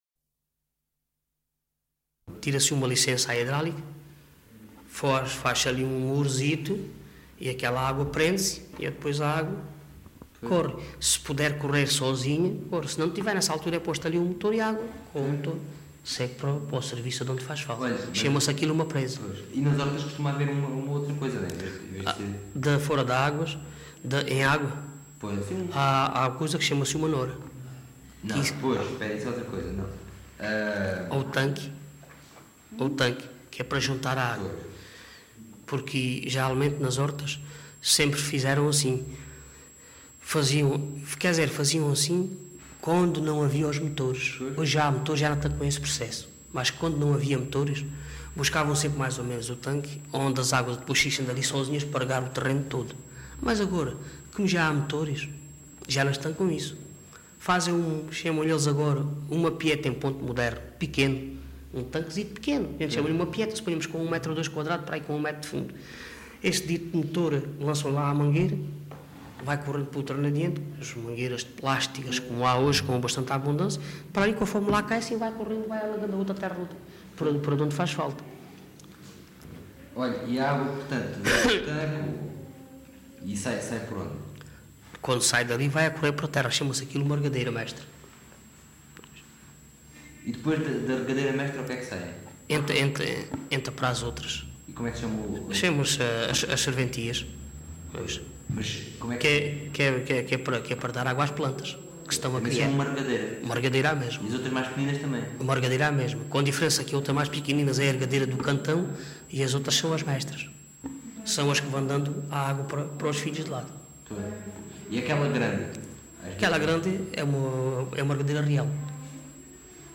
LocalidadeSerpa (Serpa, Beja)